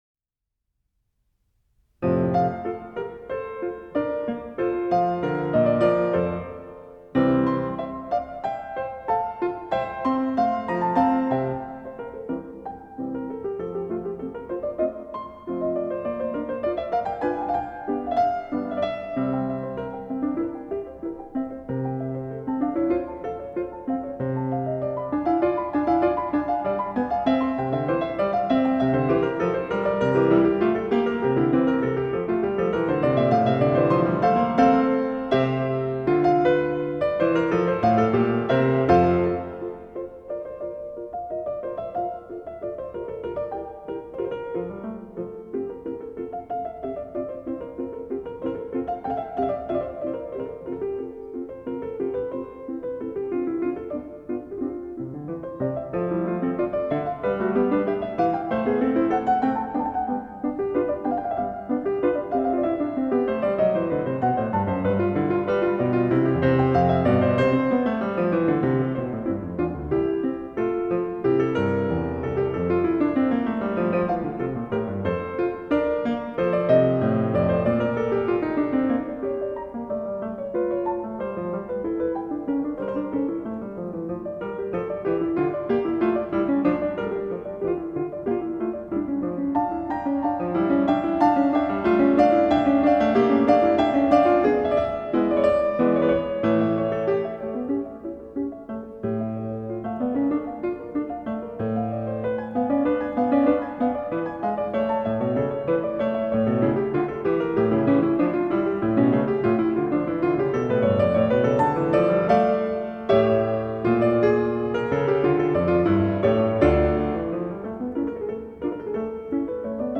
موسیقی کلاسیک: کنسرتو ایتالیایی از یوهان سباستین باخ با اجرای ماهان اصفهانی - Mahan Esfahani - J.S. Bach Italian Concerto, BWV 971